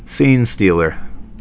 (sēnstēlər)